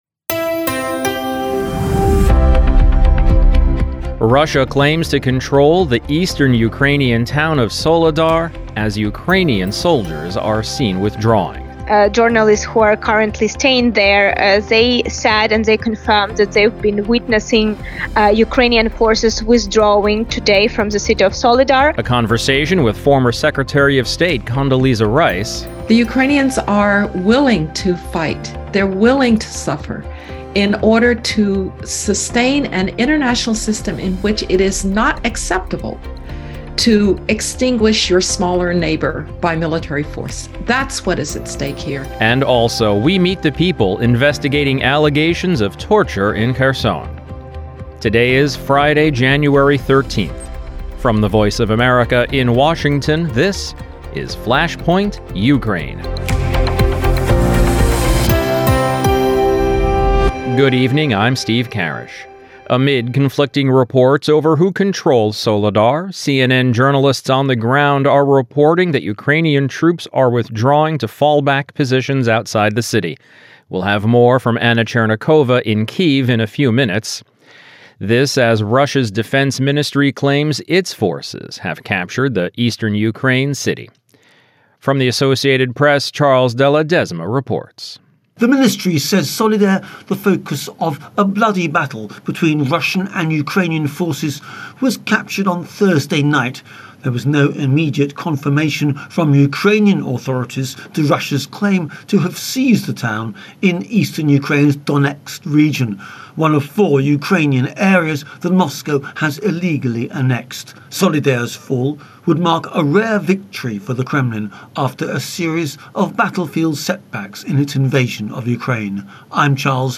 Russia claims to have captured Soledar; we’ll hear from Moscow and Washington. Also, a look at investigating war crimes in Kherson. Plus a conversation with former Secretary of State Condoleezza Rice on the importance of supporting Ukraine.